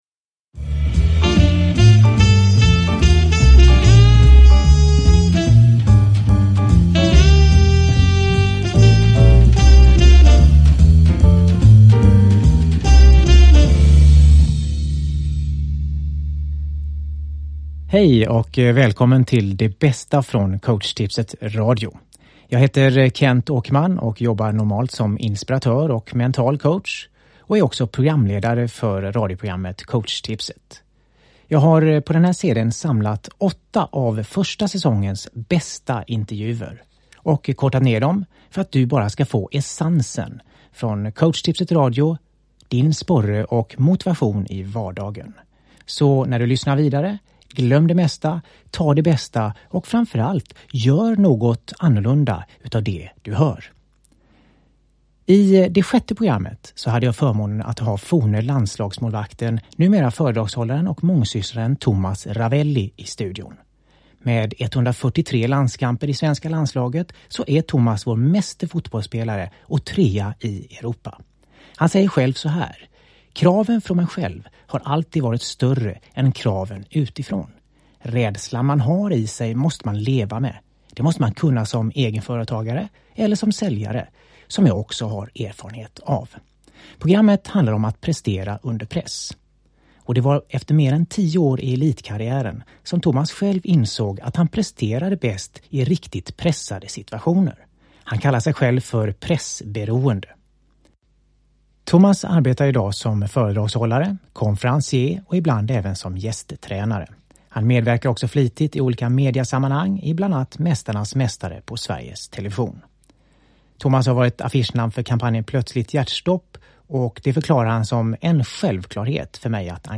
Downloadable Audiobook
Åtta inspirerande intervjuer på tema som prestation, framgång, teamarbete, relationer, hypnos, förverkliga sina drömmar med flera.